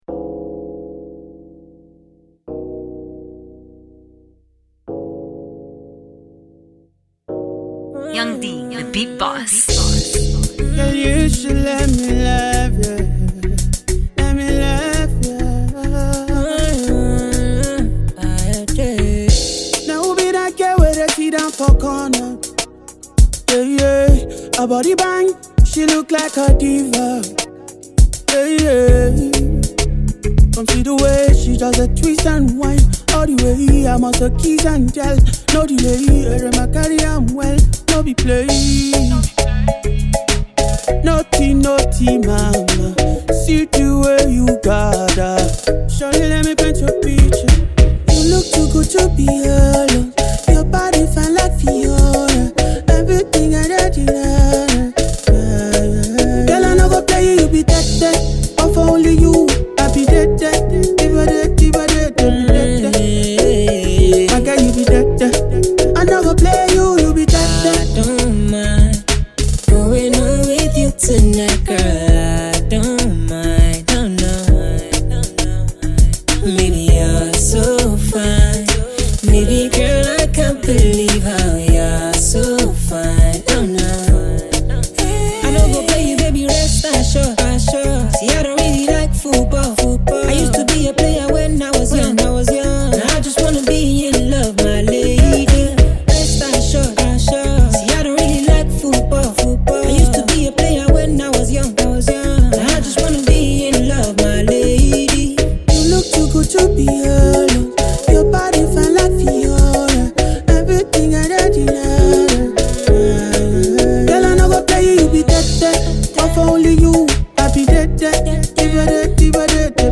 Afro reggae/dancehall
catchy tune